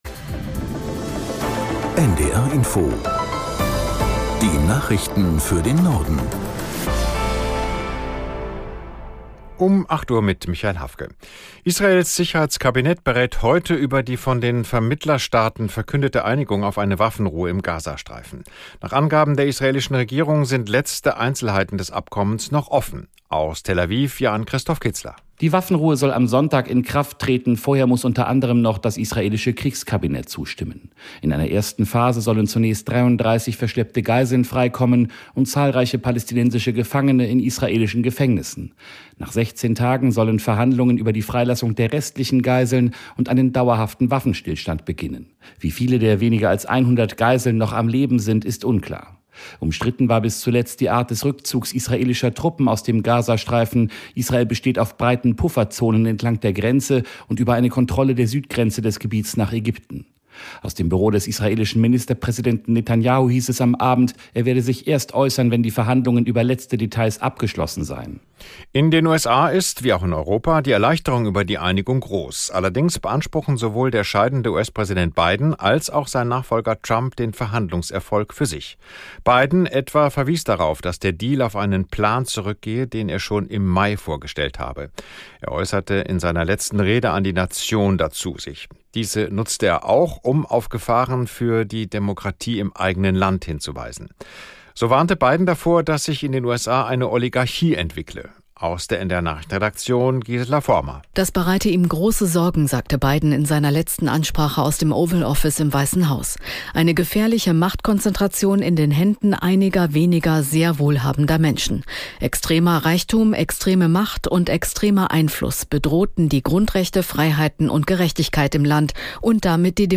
Nachrichten - 16.01.2025